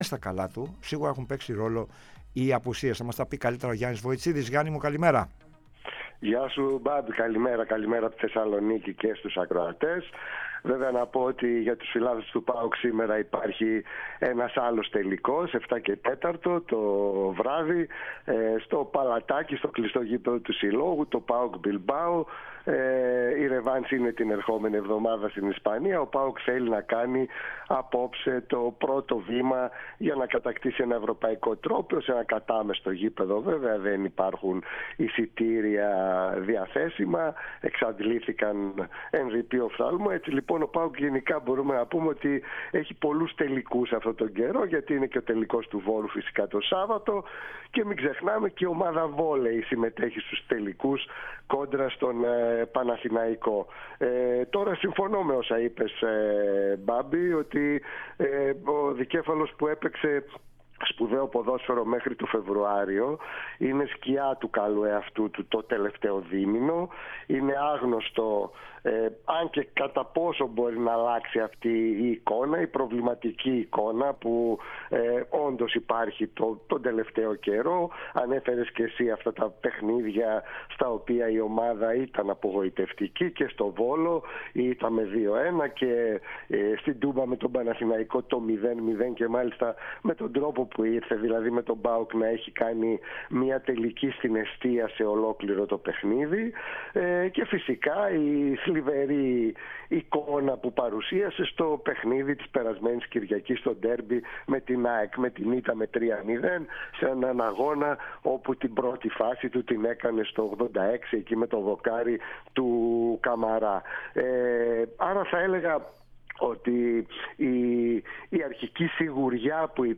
μίλησε στην εκπομπή "Σύστημα 3-5-2" μεταφέροντας όλα τα νέα του "Δικέφαλου του Βορρά".